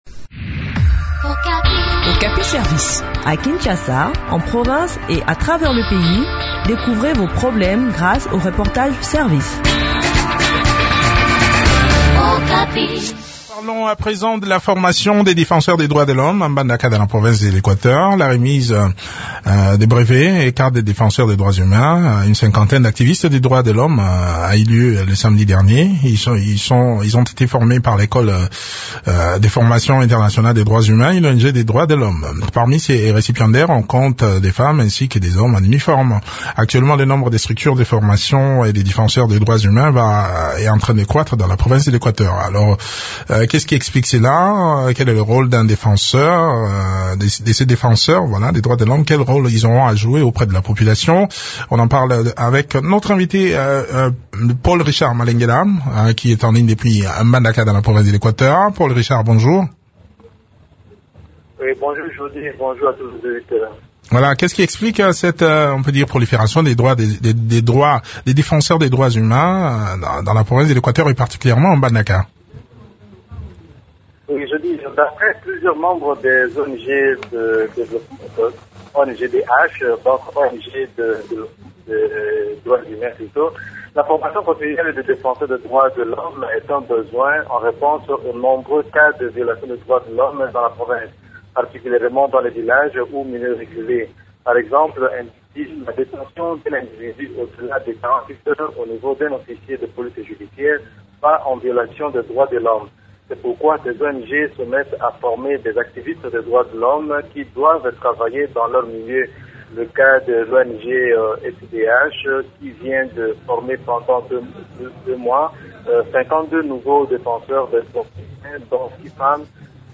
Le point sur l’organisation de cette session de formation dans cet entretien